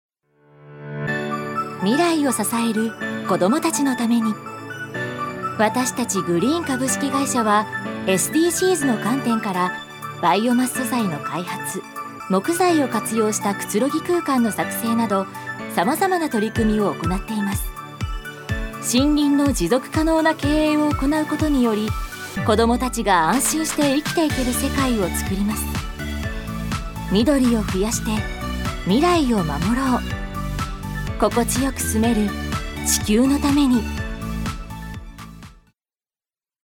女性タレント
ナレーション７